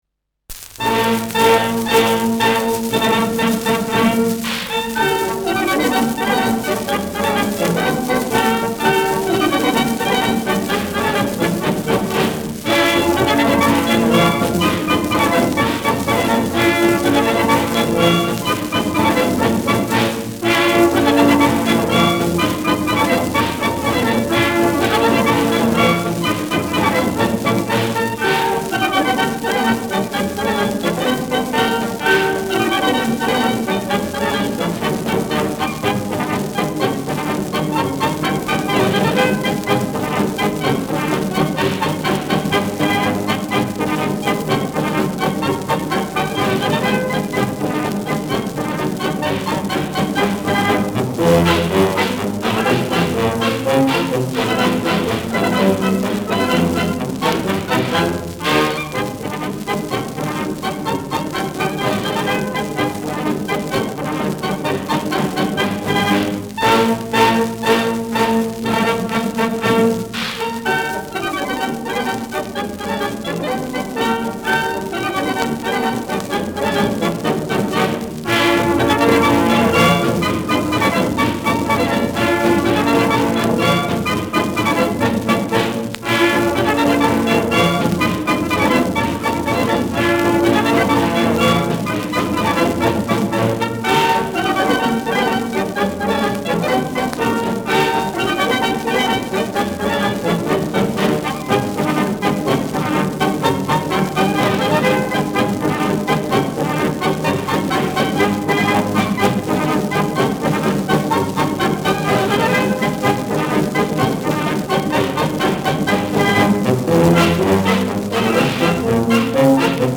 Schellackplatte
präsentes Rauschen : präsentes Knistern : vereinzeltes Knacken : Knacken und „Hängen“ bei 2’40’’
Kapelle Moar (Interpretation)
[Berlin] (Aufnahmeort)